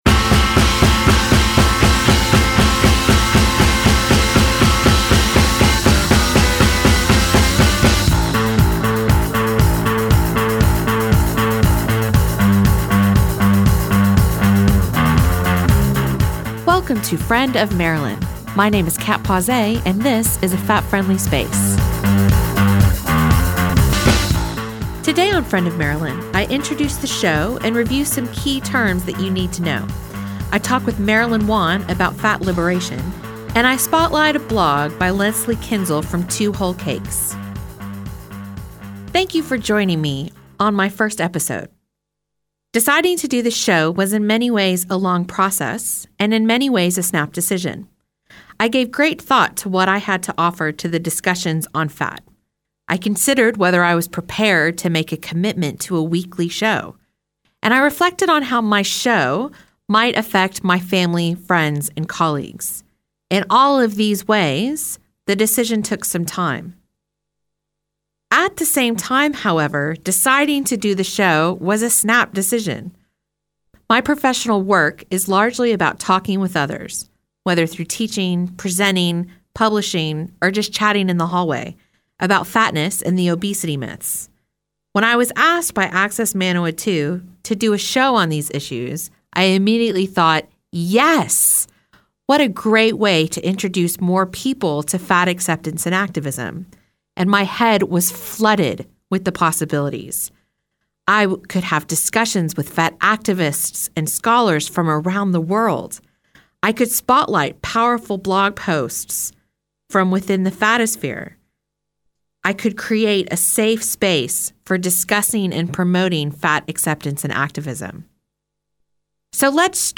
This episode has been edited from version originally broadcast to remove music by Gossip as rights and licences were not obtained by Manawatū Heritage.